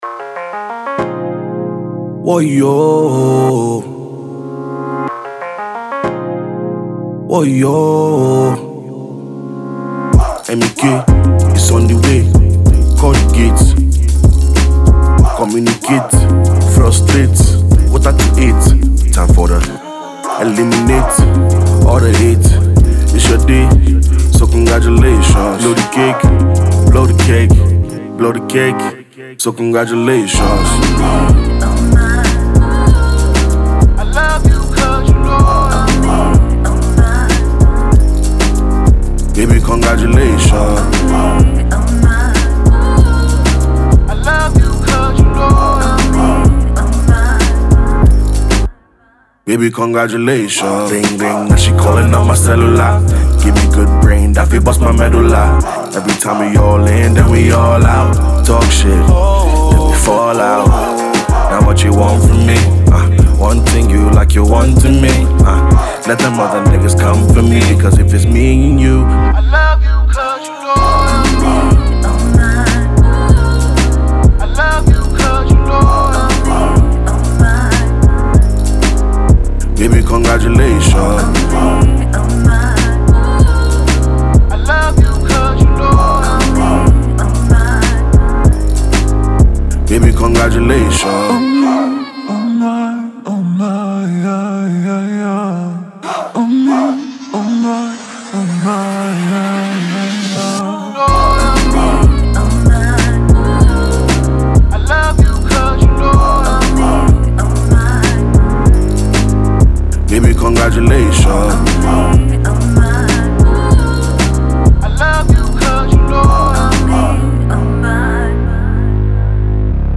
alternative pop
smooth signature track
sonically appealing